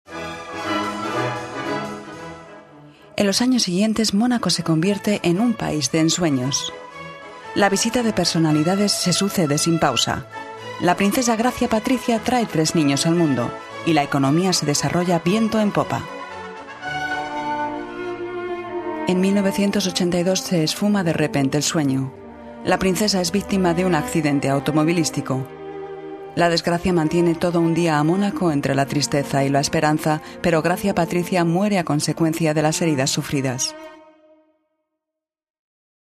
» Spanisch f.